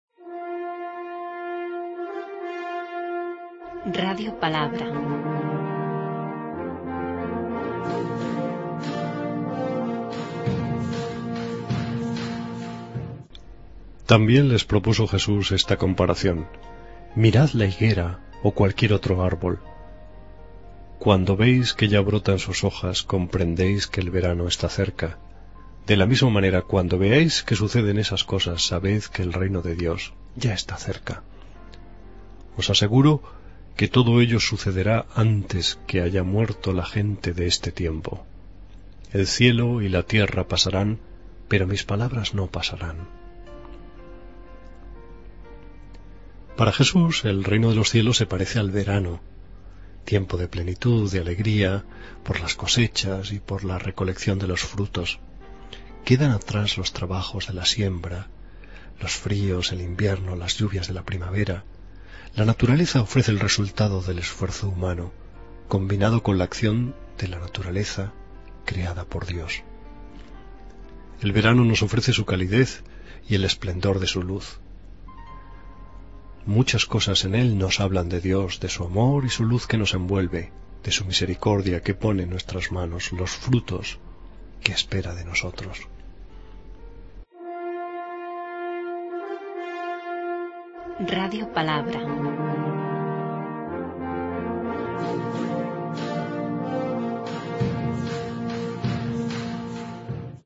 Lectura del santo evangelio según san Lucas 21,29-33